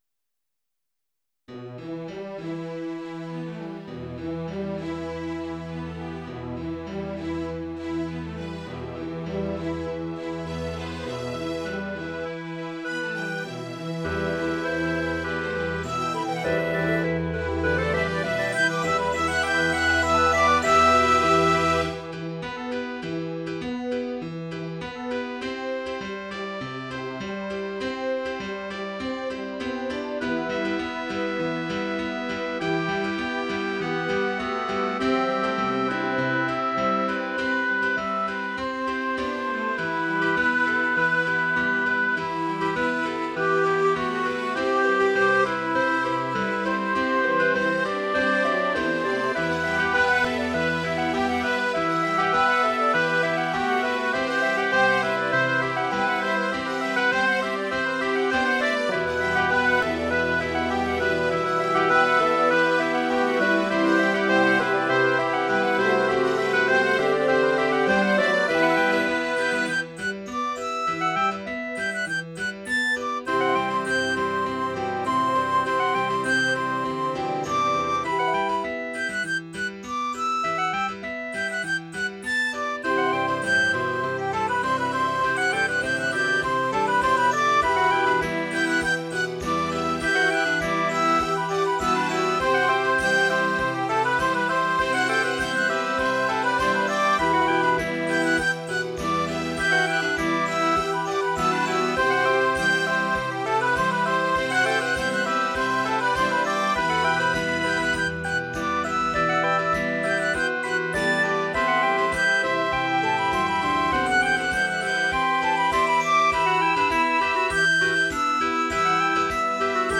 Tags: Piano, Clarinet, Woodwinds, Strings, Brass, Percussion